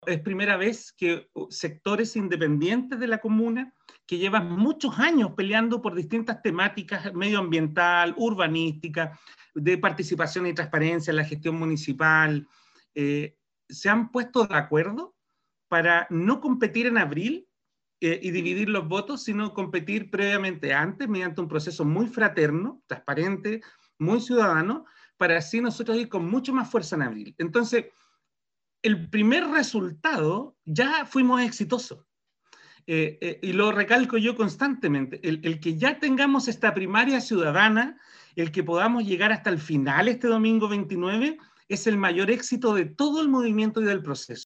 En entrevista con RadioUdeC